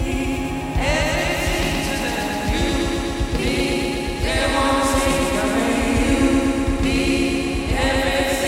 Echo/Reverb When I Record Using Stereo Mix
When I try to record using Stereo Mix, I get this horrible echo/reverb effect.
It creates a feedback loop when you record the sound coming out of your soundcard.